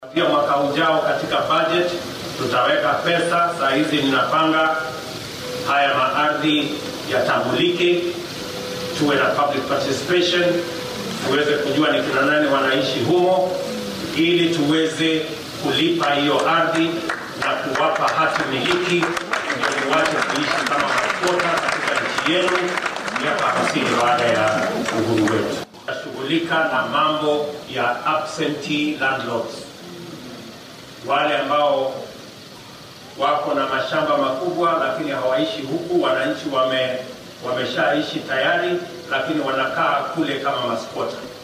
Madaxweynaha dalka William Ruto ayaa ka hadlay qorshayaashiisa horumarineed ee ku aaddan gobolka Xeebta ee wadanka. Xilli uu shalay ku sugnaa ismaamulka Kilifi ayuu ballanqaaday in uu wax ka qaban doono arrimaha dhulka, islamarkaana uu soo nooleyn doono dhaqaalaha gobolka Xeebta.